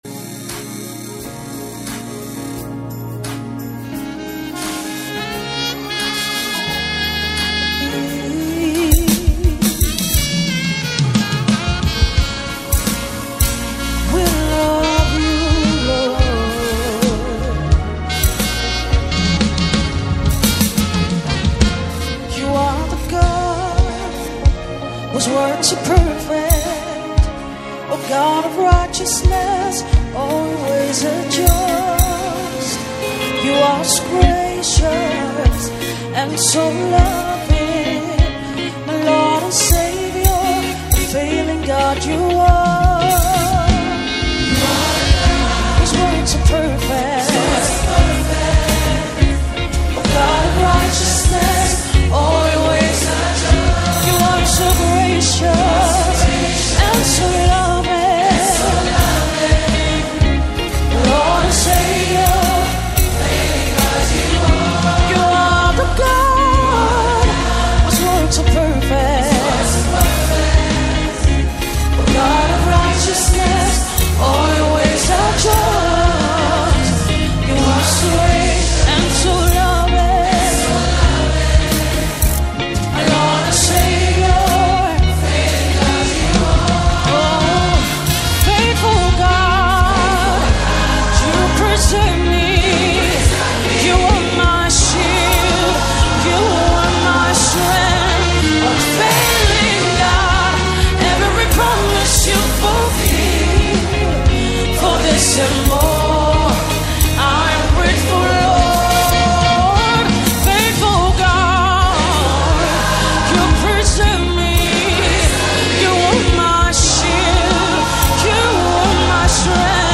Key – C sharp